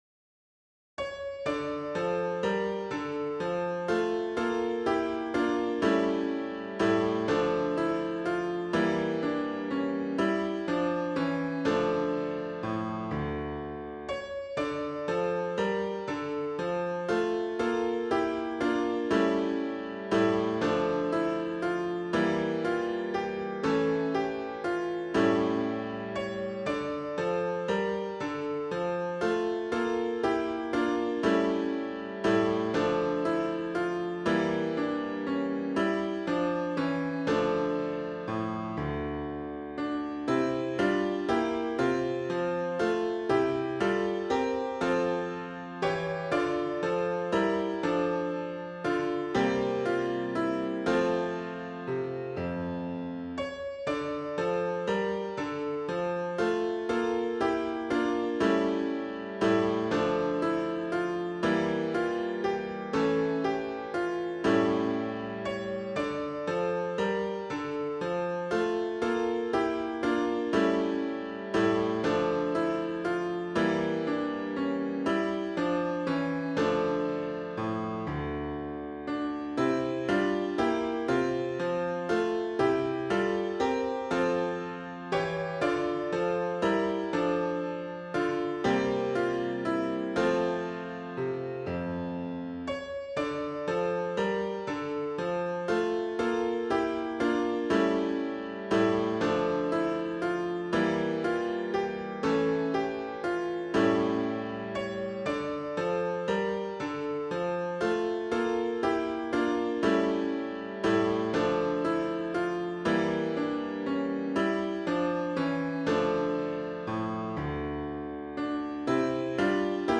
Children’s Song with Piano